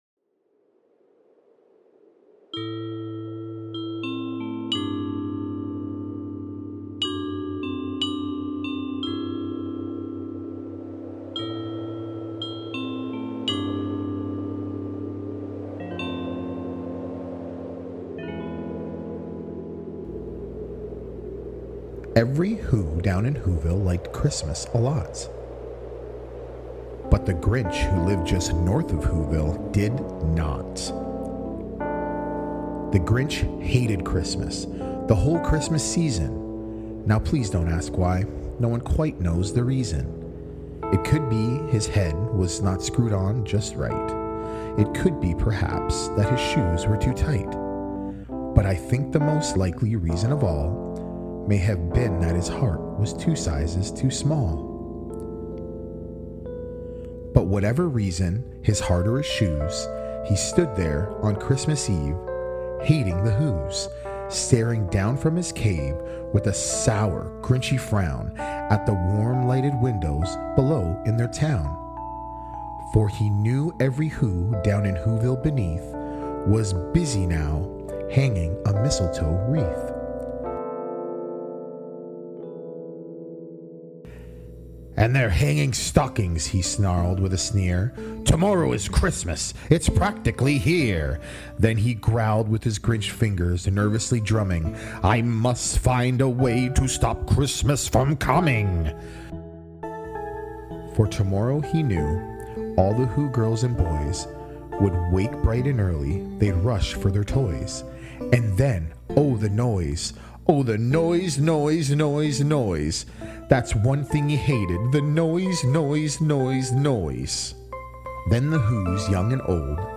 Please enjoy this Reading of How The Grinch Stole Christmas!